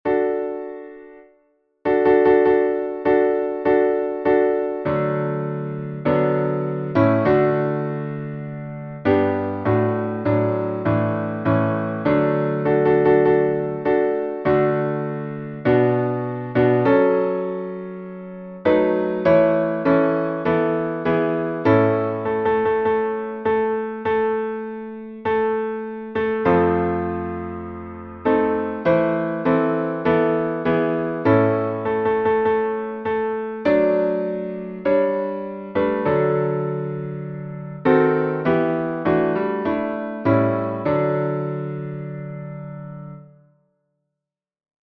salmo_50B_instrumental.mp3